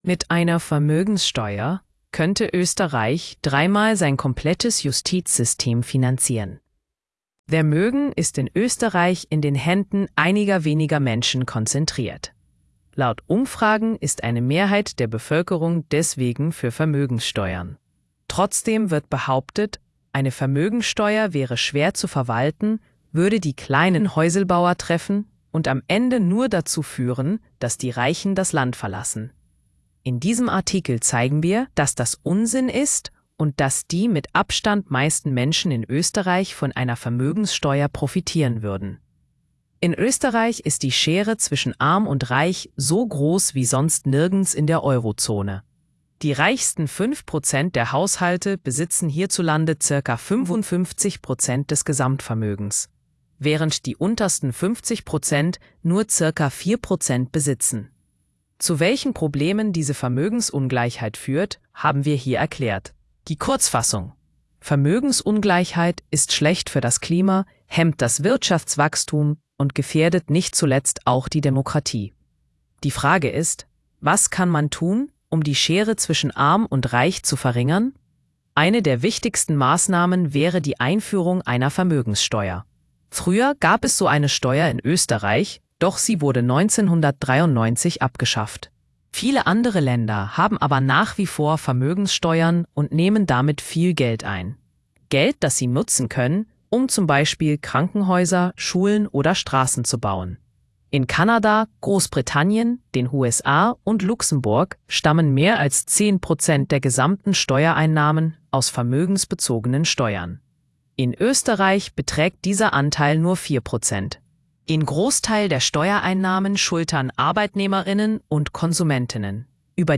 Hallgassa meg a cikk hangos verzióját (mesterséges intelligencia által generálva).